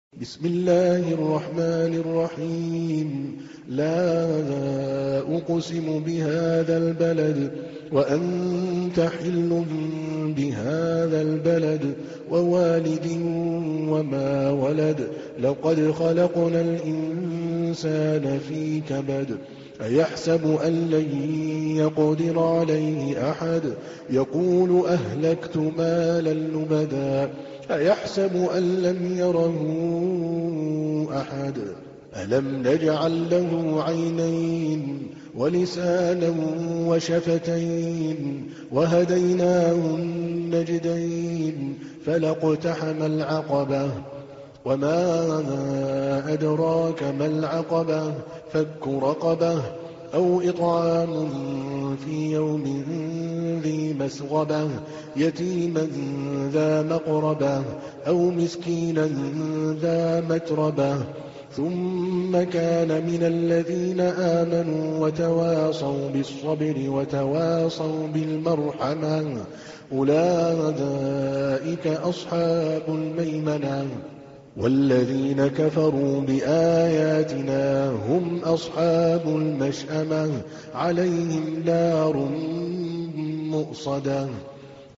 تحميل : 90. سورة البلد / القارئ عادل الكلباني / القرآن الكريم / موقع يا حسين